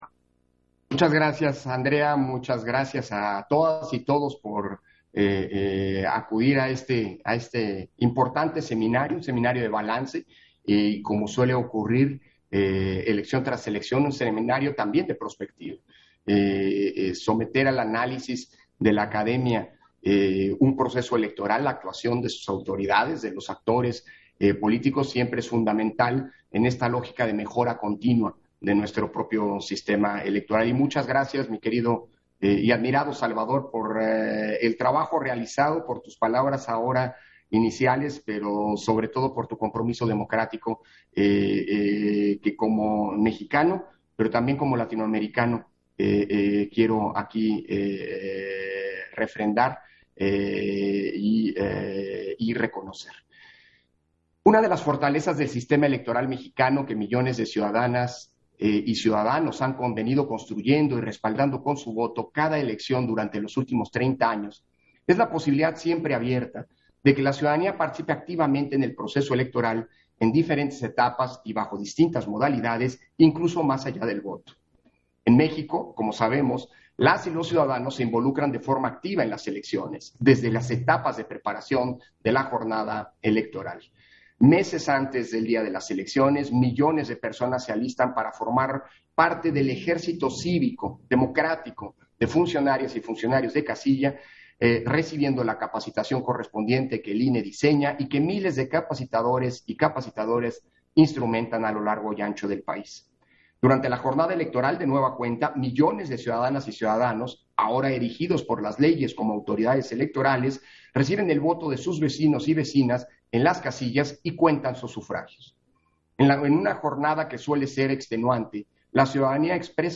Intervención de Lorenzo Córdova, en la inauguración del Seminario de Presentación de Investigaciones de Centros Académicos y universidades, del fondo de apoyo a la observación electoral 2021